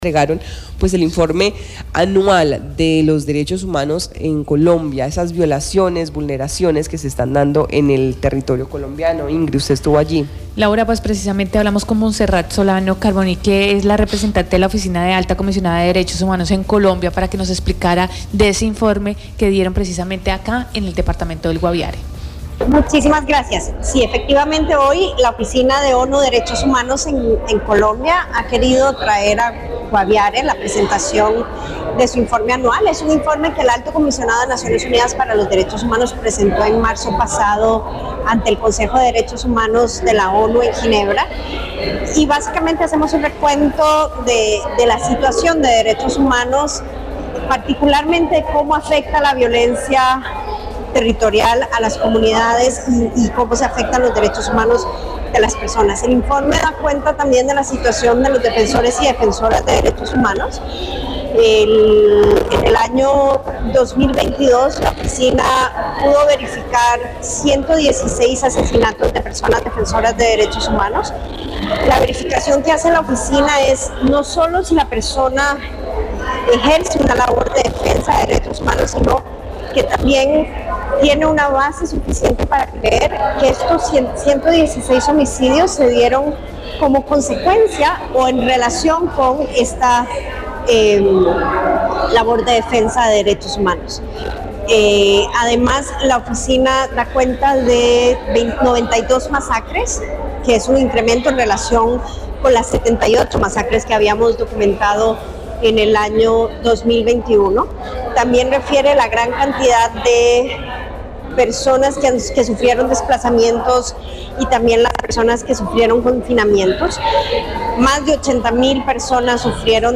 En las instalaciones de Facredig, en el Guaviare, se presentó el informe anual sobre la situación de los derechos humanos en Colombia por parte de las Naciones Unidas.